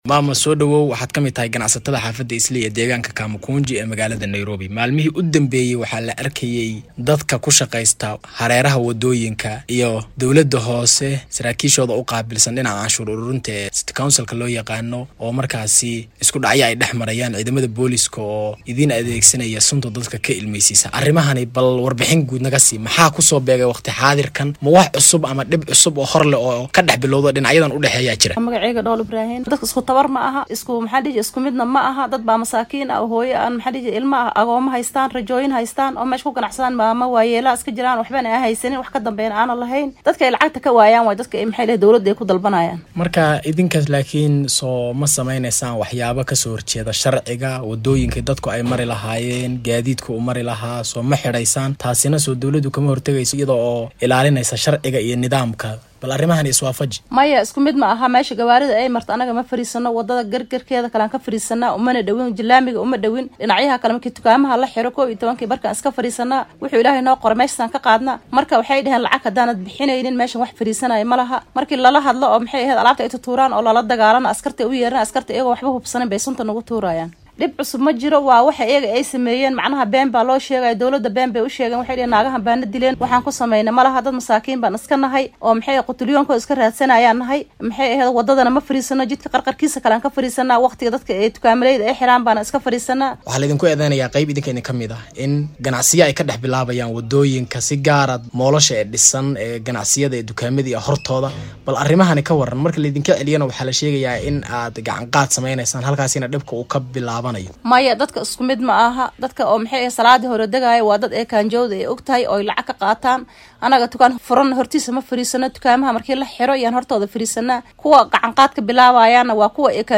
ayaa wareystay hooyo ka mid ah waratada xaafadda Islii waxayna ka shekeynaysaa dhibaatooyinka maalinlaha ah ee ay wajahaan.